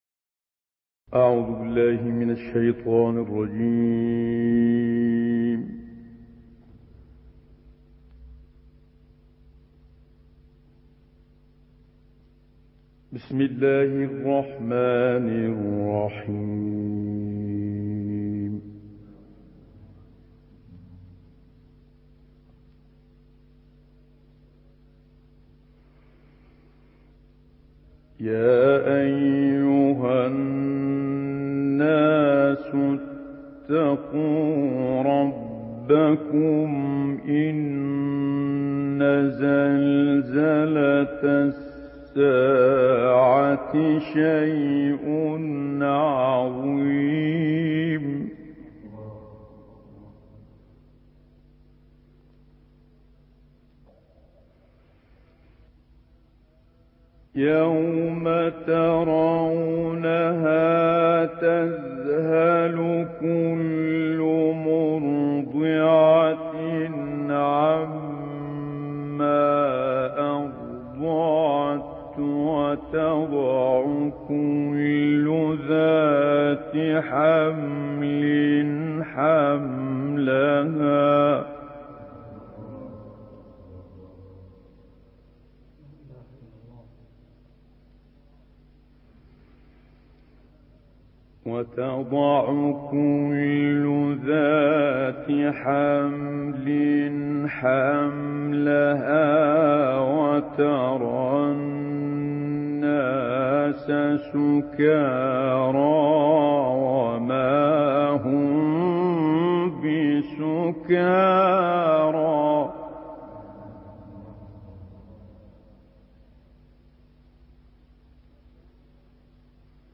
تلاوت آیاتی از سوره حج توسط استاد شیخ متولی عبدالعال